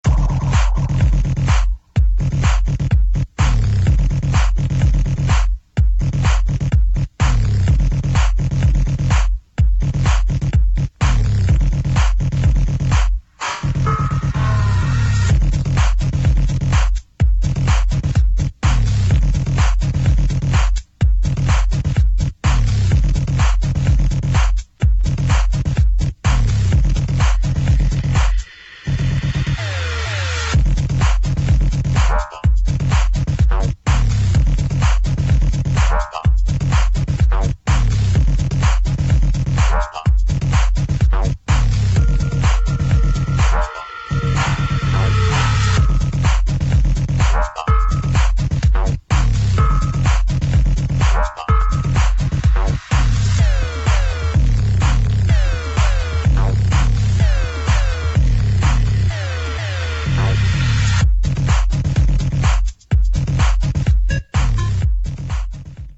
[ TECH HOUSE / PROGRESSIVE HOUSE ]